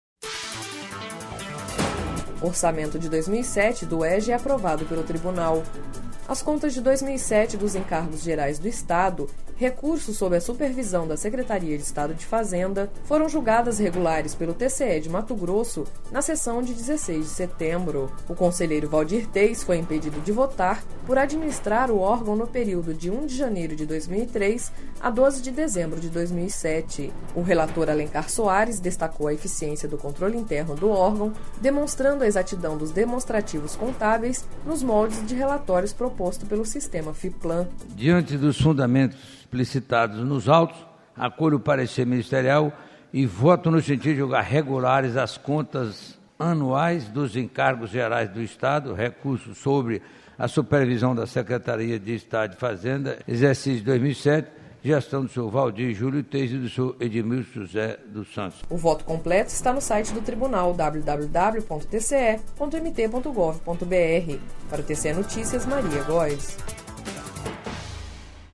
Sonora: Alencar Soares - conselheiro do TCE-MT